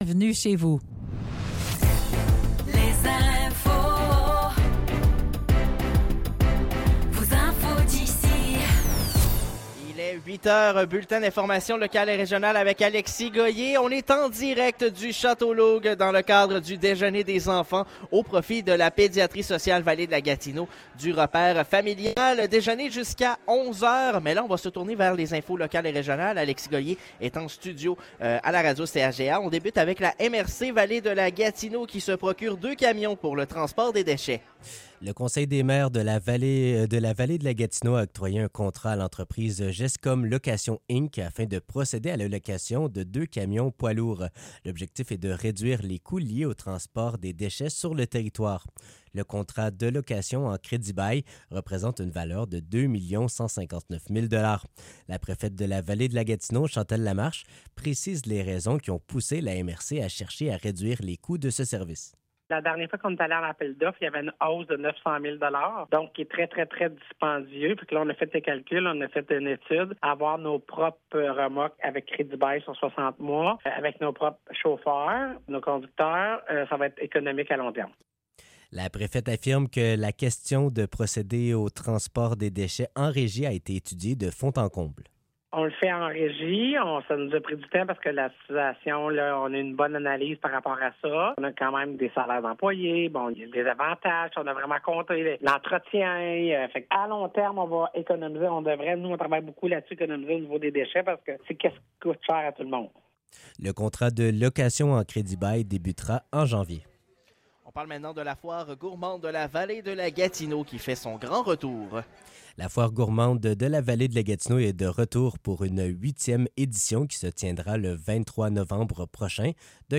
Nouvelles locales - 20 novembre 2024 - 8 h